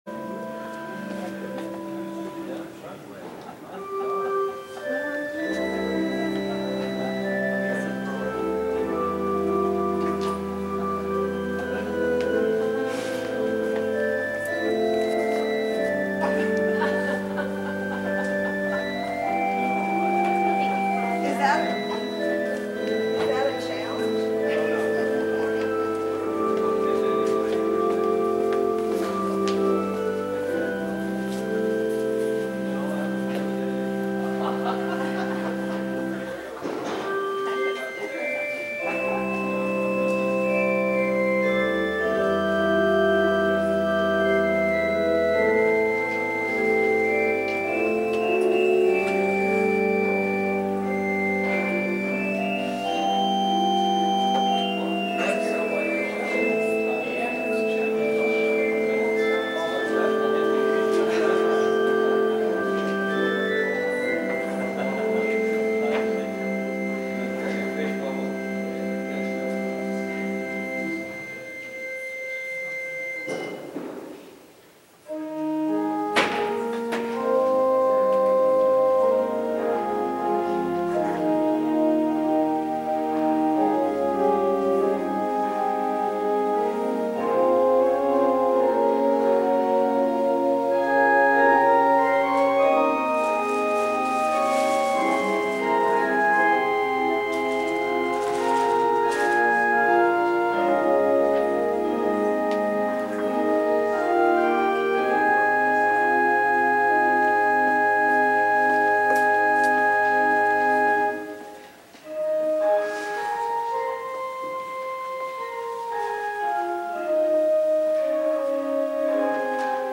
The simple, lyric, melodic piping.
Remember,  these are clips of live performance, people coming and leaving the service. Regular service music. That’s why the chatter in the background.
prelude-8-30.mp3